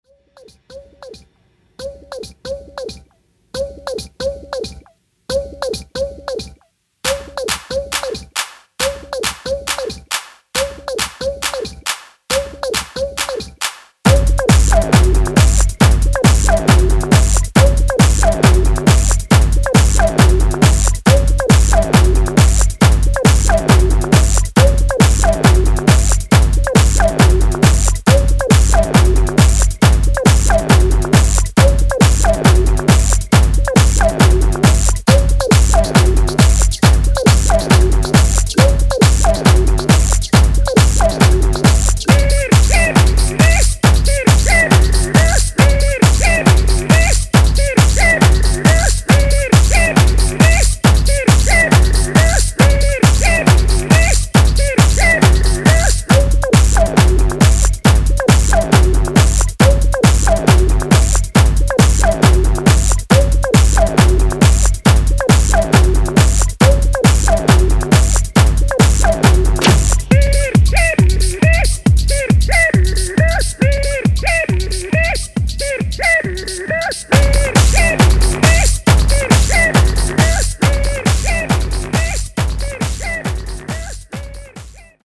Electronic
Techno, Hard Techno, Hardstyle, Jumpstyle